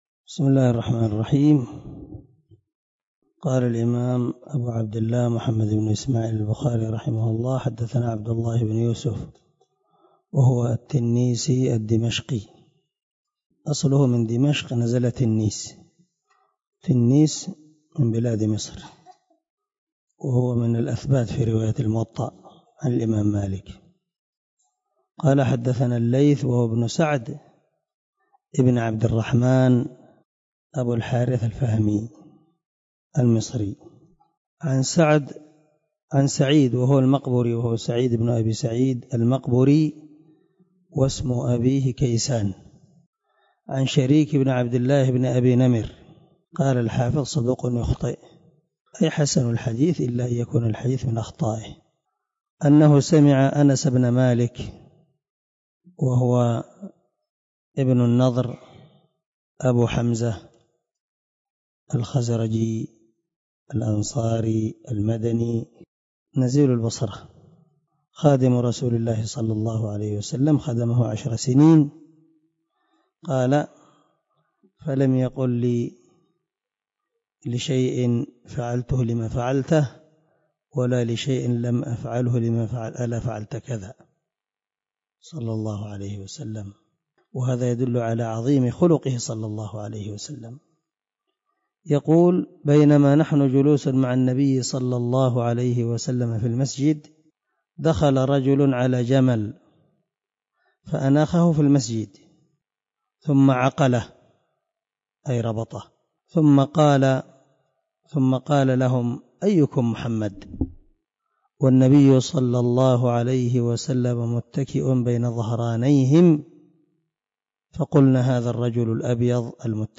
061الدرس 6 من شرح كتاب العلم حديث رقم ( 63 ) من صحيح البخاري